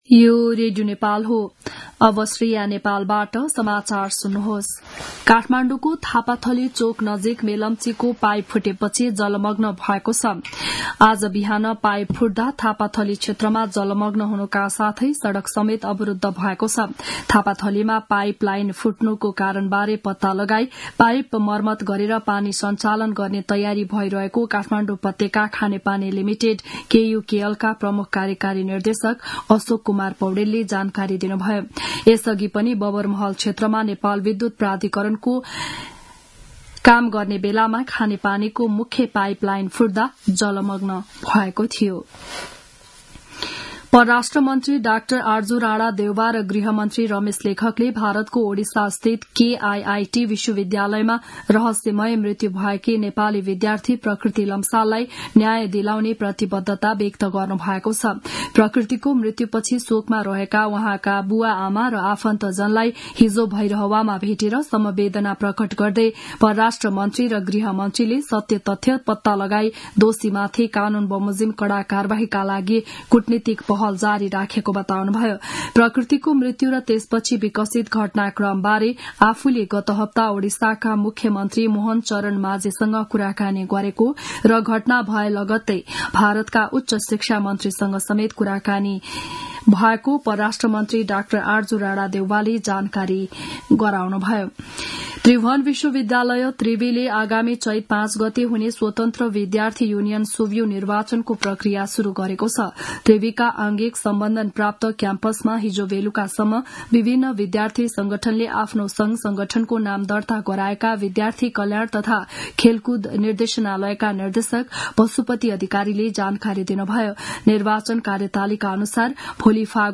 बिहान ११ बजेको नेपाली समाचार : २१ फागुन , २०८१
11-am-news-1-1.mp3